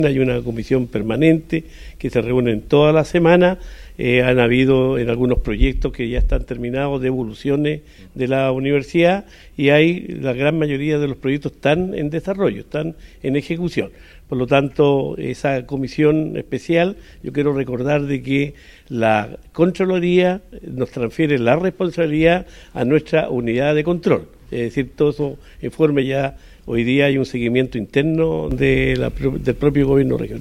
El gobernador regional de Los Rios, el socialista Luis Cuvertino, señaló incluso que la casa de estudios ha devuelto dinero, en el marco de la labor de seguimiento y control que realiza una unidad del Gobierno Regional por lo mandatado por la Contraloría.